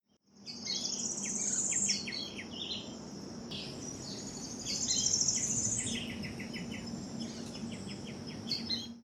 Anambé Común (Pachyramphus polychopterus)
Anambé común acompañado por Arañero coronado chico, Pijui frente gris y Chivi común.
Localidad o área protegida: Reserva Ecológica Costanera Sur (RECS)
Condición: Silvestre
Certeza: Vocalización Grabada